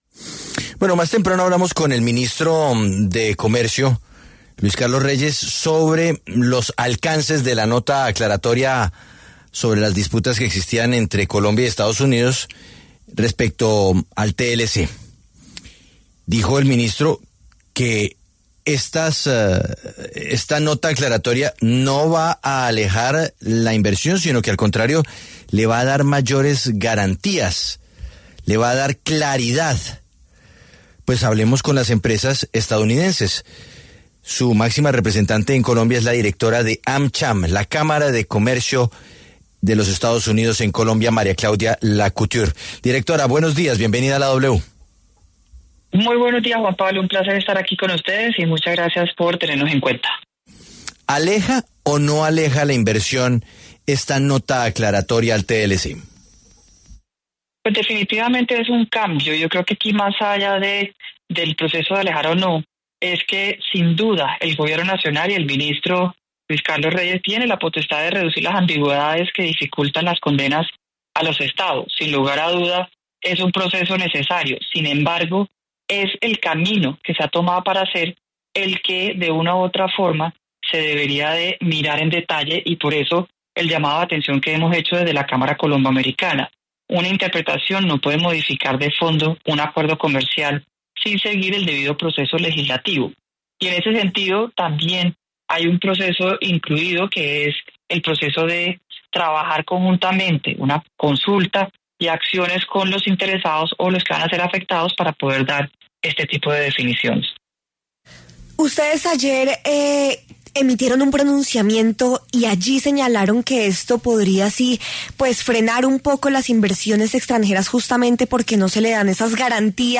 María Claudia Lacouture, director de AmCham, pasó por los micrófonos de La W, con Julio Sánchez Cristo, para hablar sobre el acuerdo al que llegaron Colombia y Estados Unidos de firmar una nota aclaratoria sobre algunos puntos del Tratado de Libre Comercio (TLC).